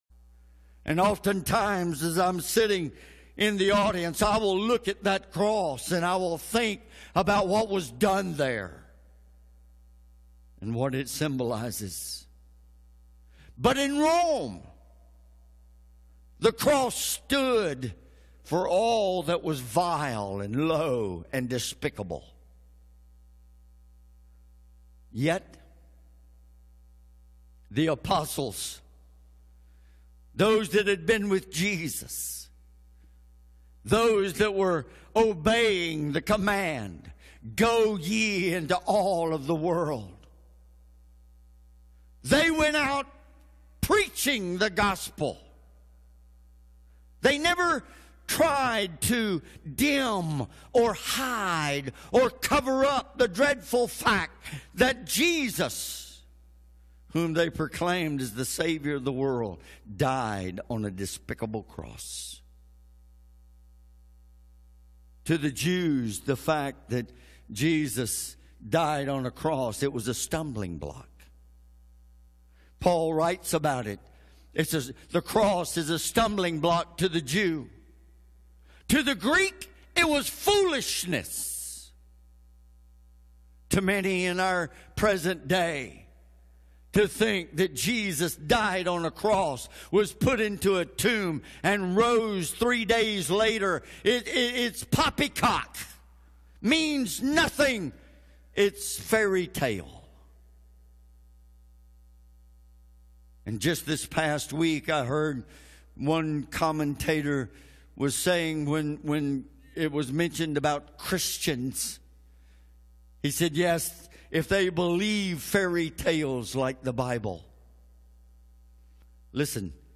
Sermons | First Assembly of God Rock Hill